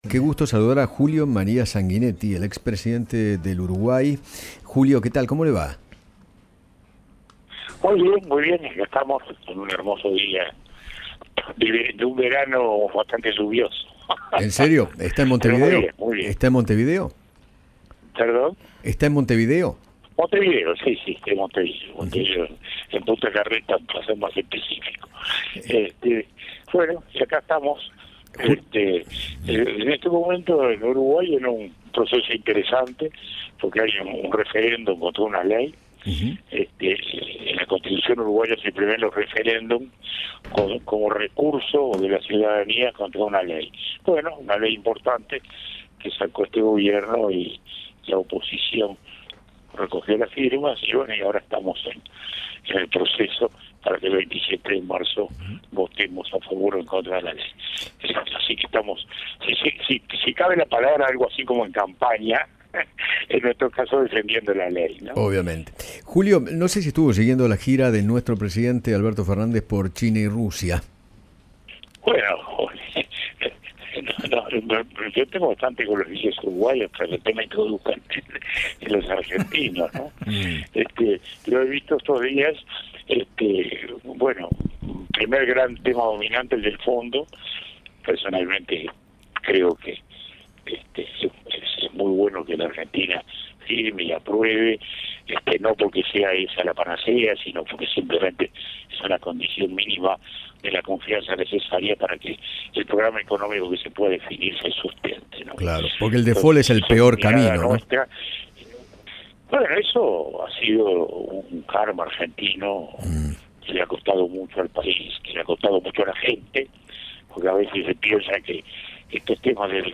Julio María Sanguinetti, el expresidente de Uruguay, habló con Eduardo Feinmann  acerca de la actualidad política de nuestro país y se refirió a las negociaciones del Gobierno con el FMI. A su vez, aseguró que “Uruguay necesita una Argentina estable”.